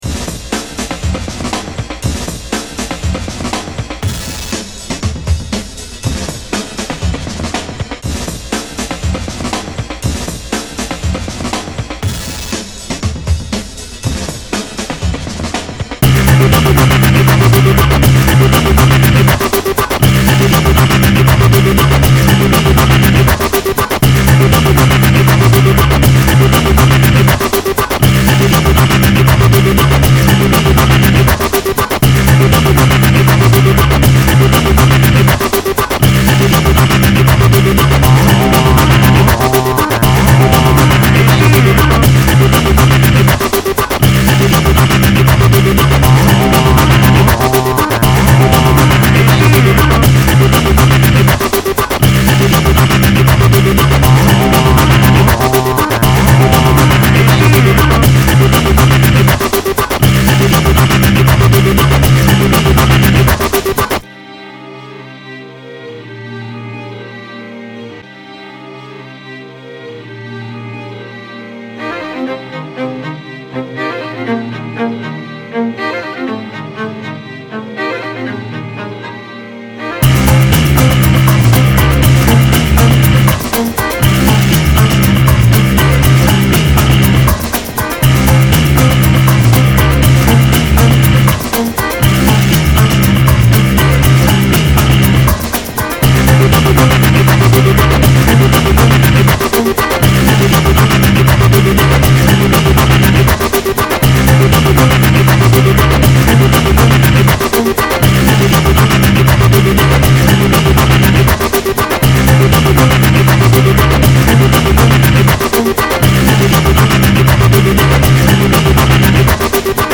New York Dance Floor Edition